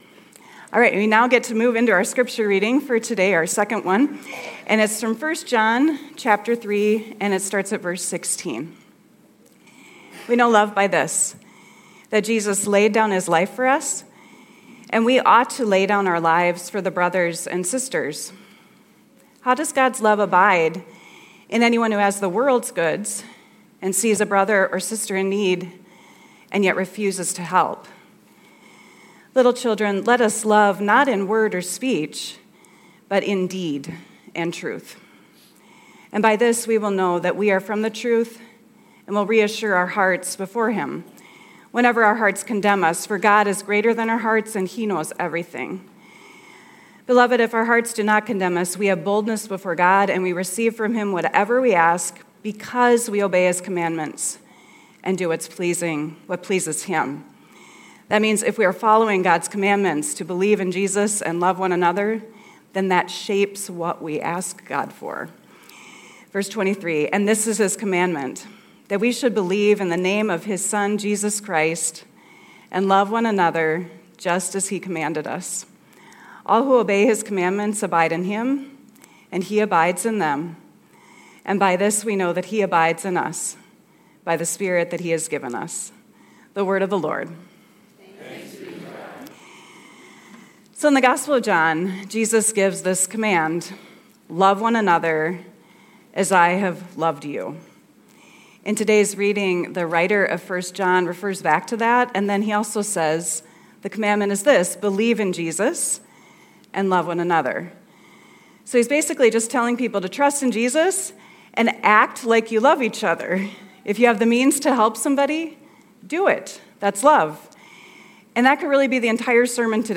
Weekly Sermons from Calvary Lutheran Church Perham, Minnesota.